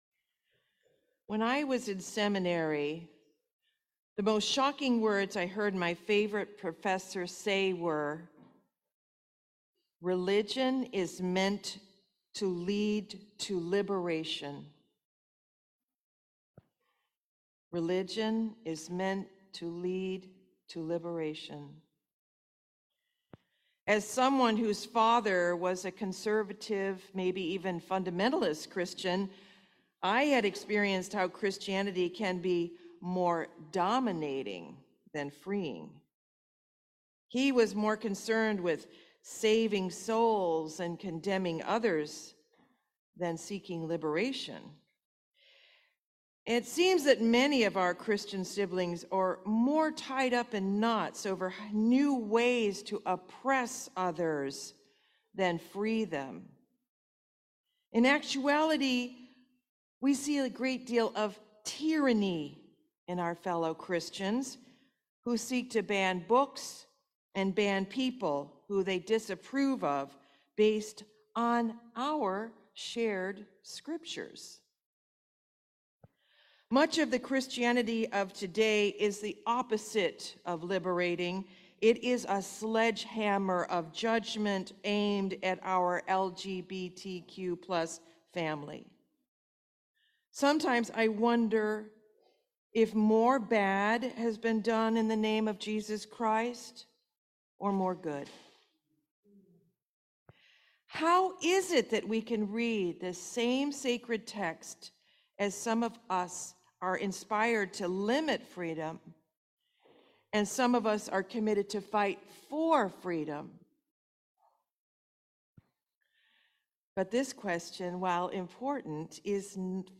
Liberation Sermon